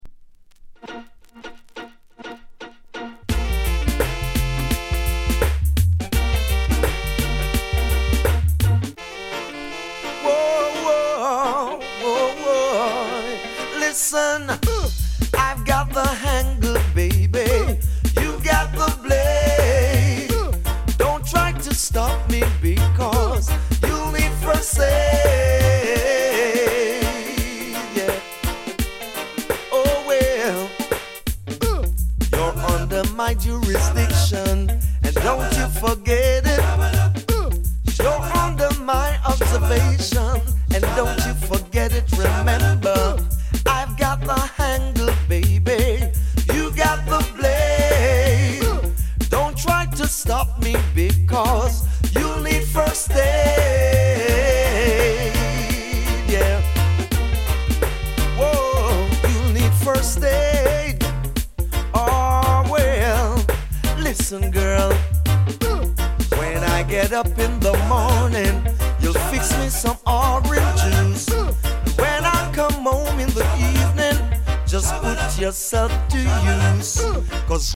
高品質 90s 唄もの *